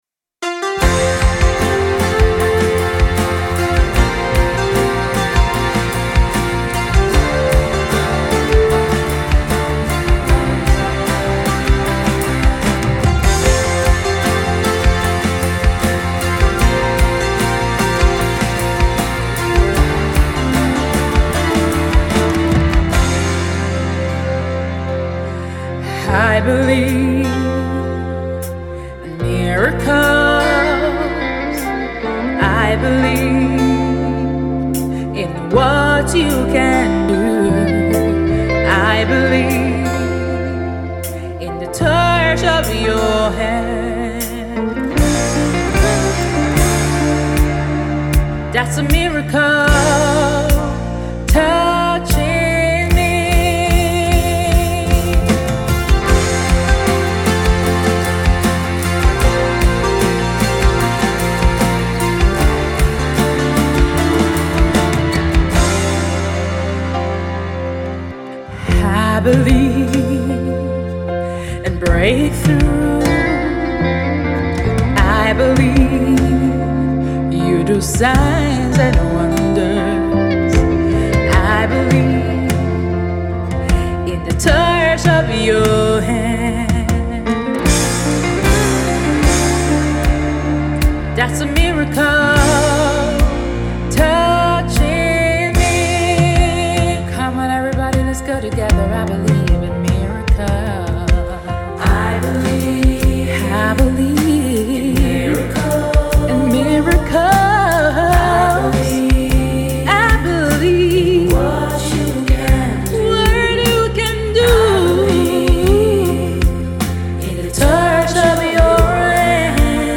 Gospel
Rock Inspirational worship Song